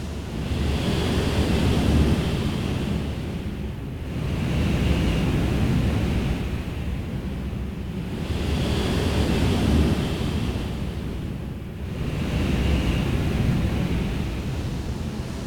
hungryBeast.ogg